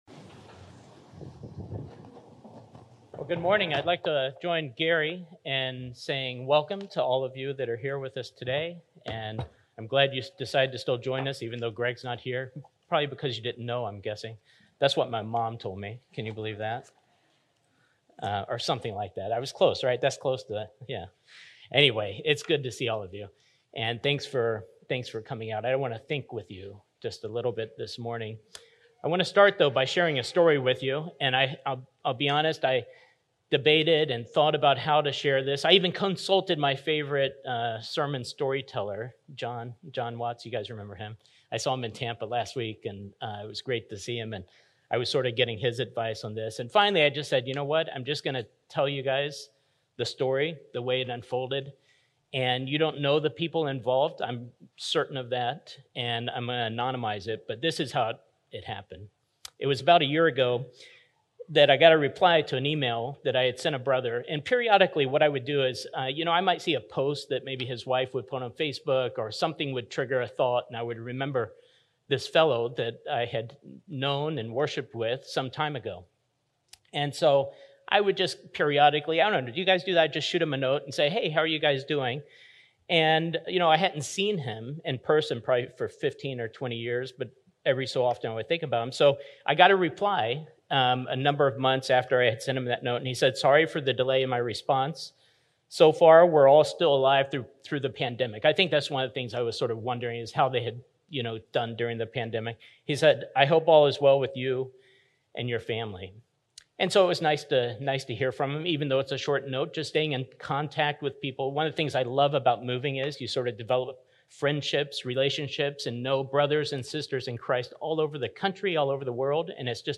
A sermon recorded on July 17, 2022.